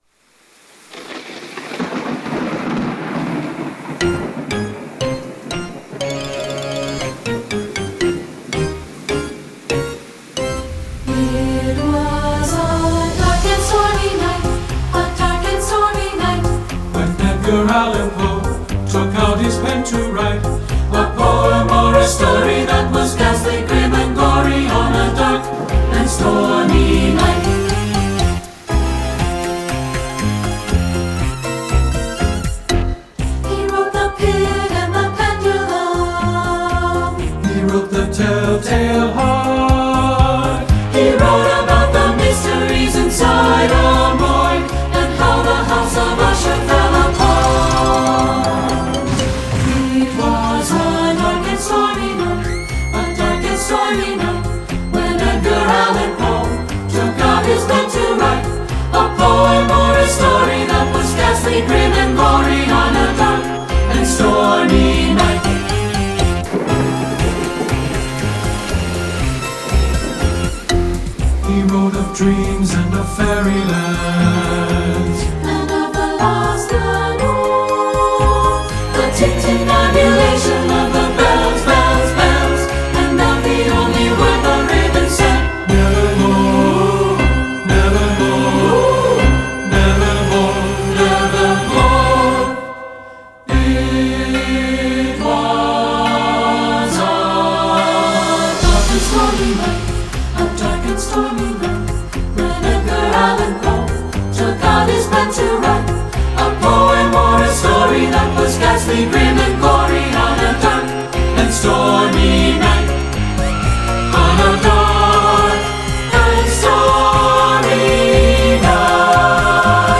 A memorable melody and clever interplay between voices
Voicing 3 Part Mix